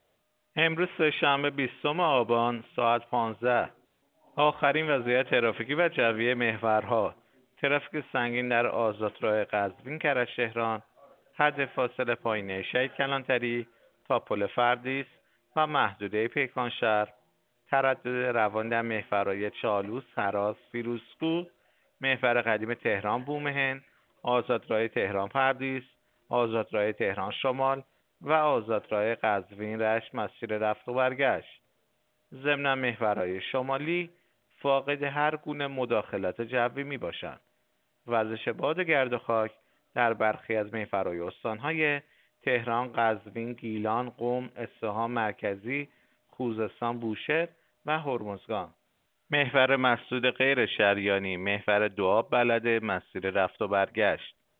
گزارش رادیو اینترنتی از آخرین وضعیت ترافیکی جاده‌ها ساعت ۱۵ بیستم آبان؛